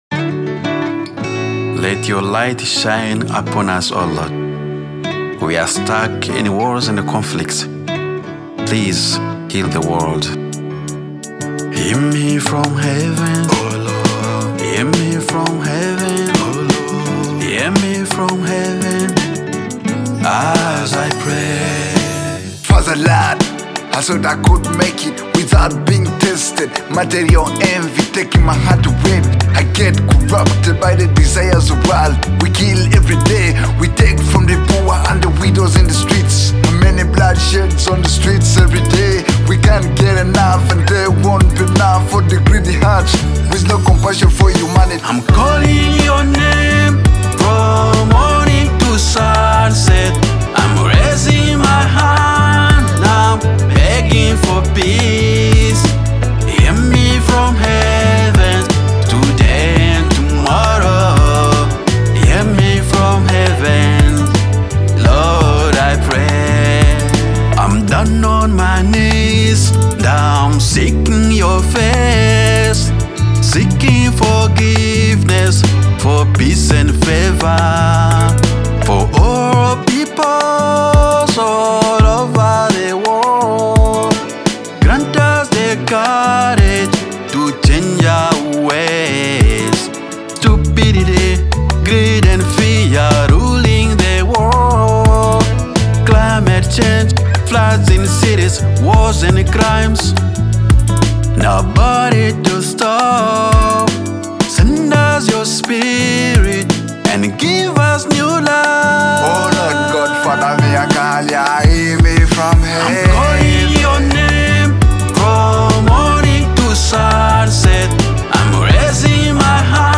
Genre: Gospel Music